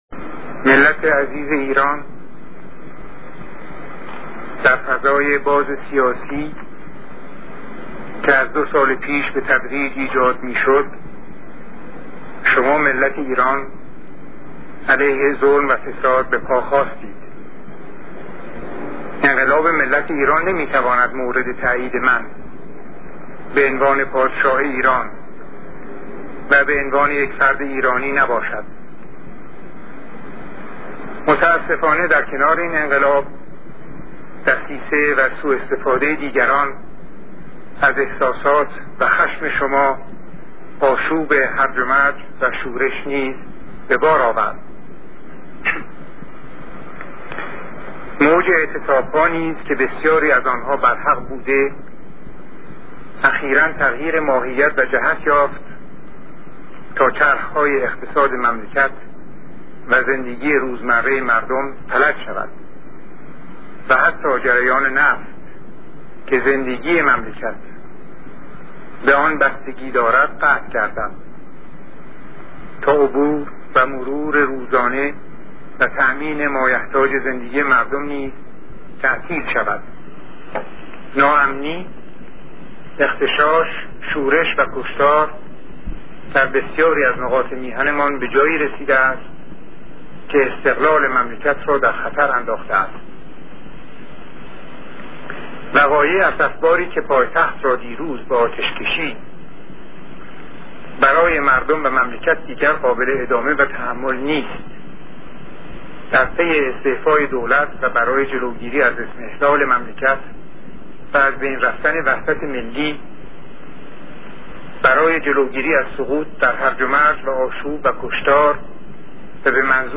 صوت / آخرین سخنرانی شاه مخلوع
آخرین سخنرانی یا همان وصیت نامه ی شاه که برای اولین بار امسال از صدا و سیما به طور کامل پخش شد